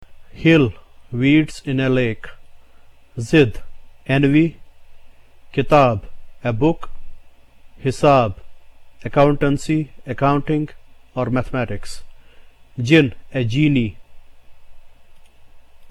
This vowel is pronounced exactly as one would the letter I in the English words PIN, SIN and so on.